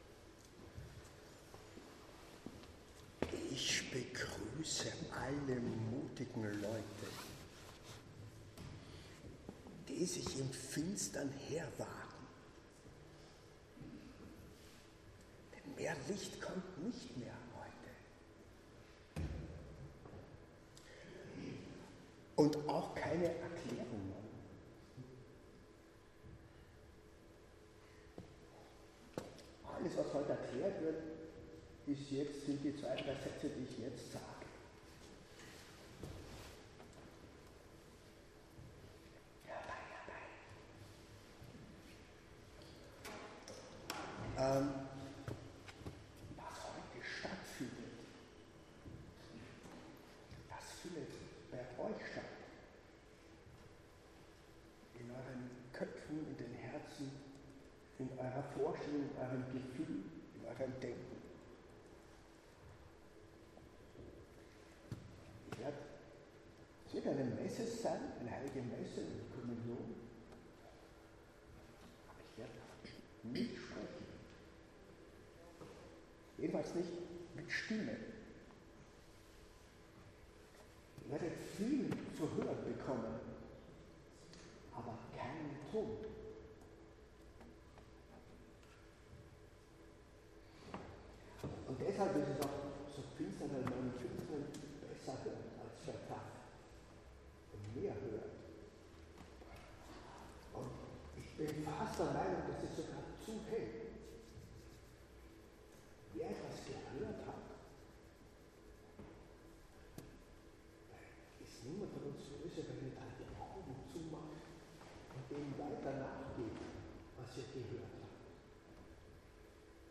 In einer Geräuschmesse hört man wahrscheinlich keinen Ton.
Hört nicht einmal eine Stimme.
Posaunist und Wahlmünchner, bekannt als origineller Interpret und Komponist zwischen Jazz und neuer Musik.
Saxofonist, Klarinettist, Flötist, Improvisator
Geräuschmesse am 11.3. um 19 Uhr in der Klagenfurter Kreuzberglkirche
(Möglichst mit Kopfhörer hören!)